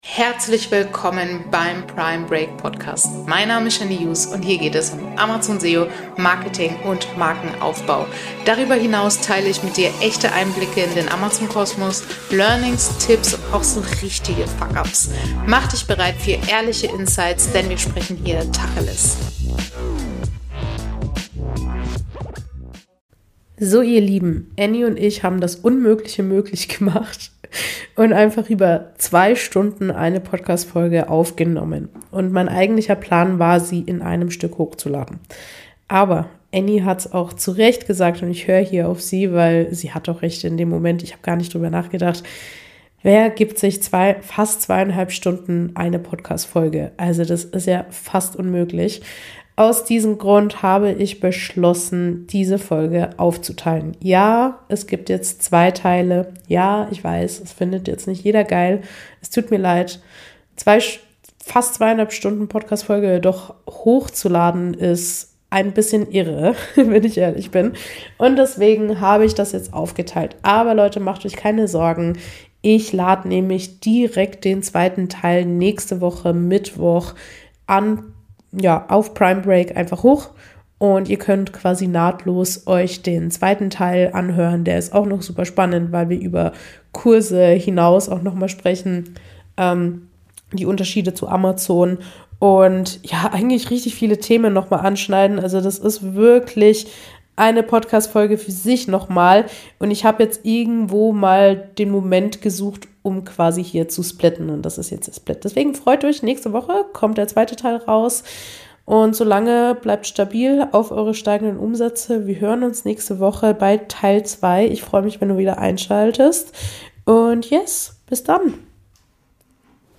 Ein ehrlicher Talk aus der Praxis – perfekt für alle, die Lust auf frische Amazon-Insights und etwas “Real Talk” aus dem Beraterinnen-Alltag haben.